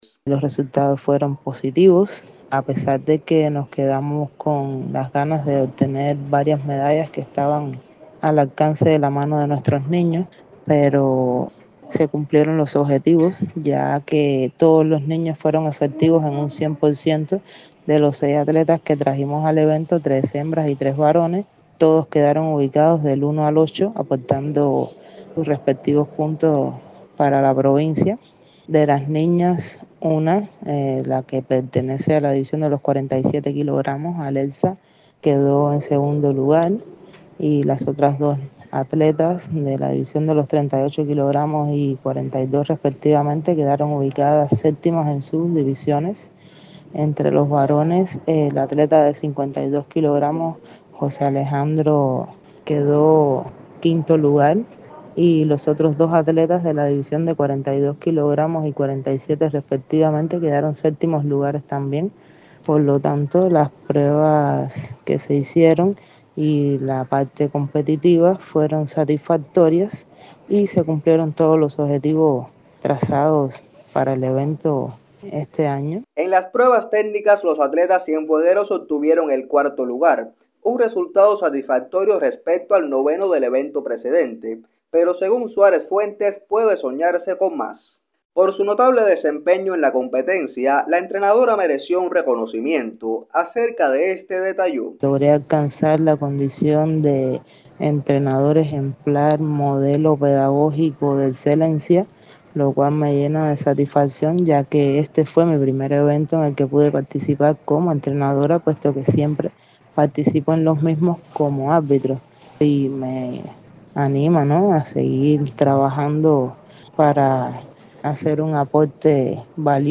Sobre las actuaciones de los atletas conversó con Radio Ciudad del Mar